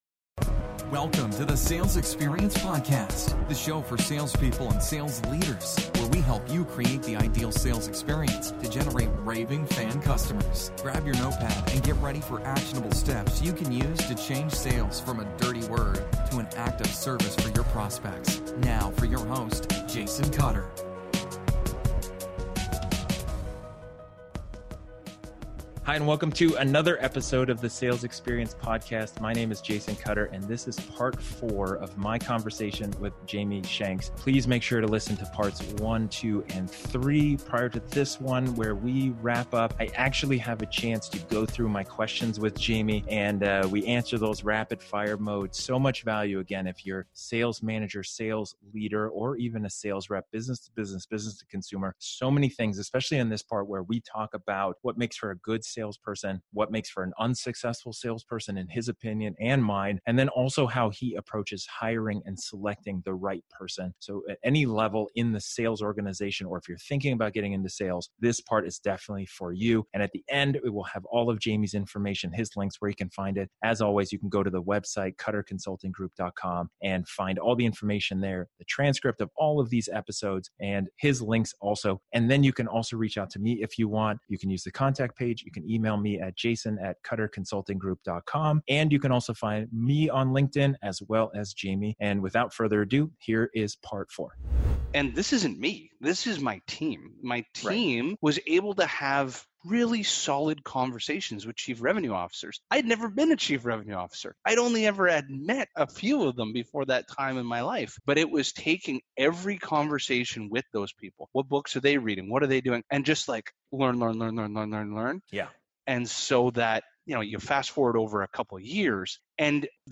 This is the final segment of the conversation